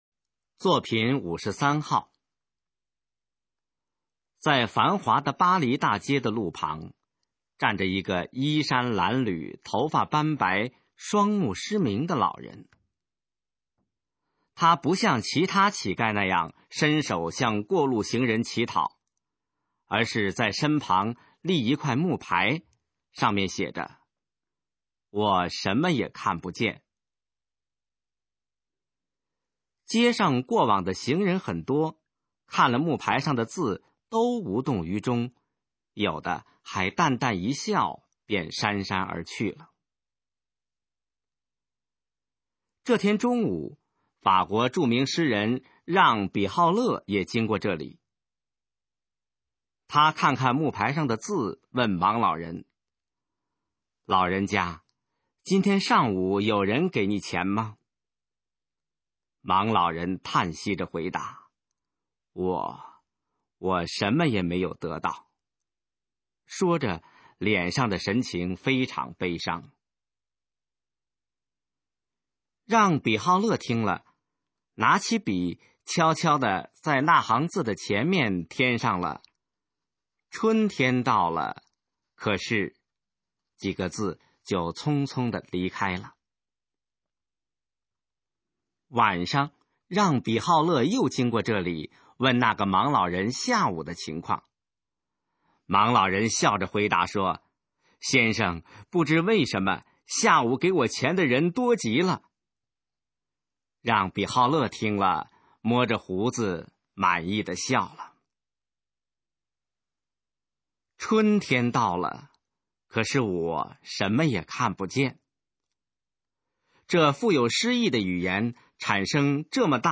首页 视听 学说普通话 作品朗读（新大纲）
《语言的魅力》示范朗读